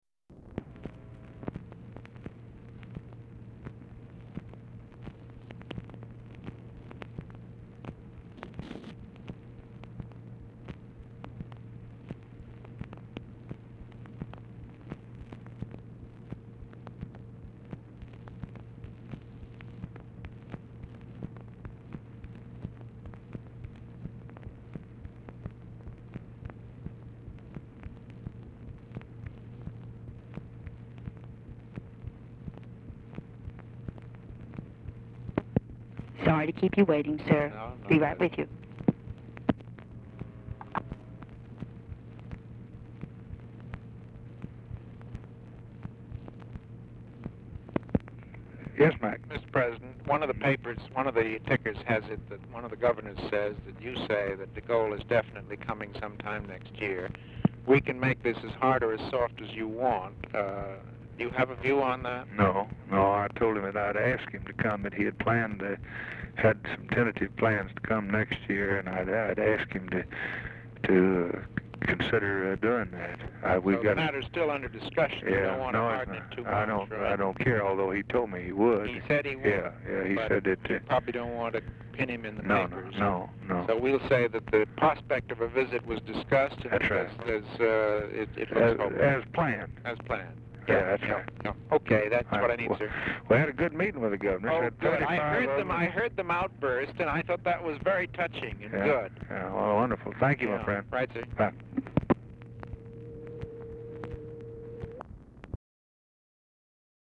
Telephone conversation # 58, sound recording, LBJ and MCGEORGE BUNDY, 11/25/1963, 9:29PM | Discover LBJ
BUNDY ON HOLD 0:50; OFFICE SECRETARY APOLOGIZES TO BUNDY FOR DELAY
Format Dictation belt
Specific Item Type Telephone conversation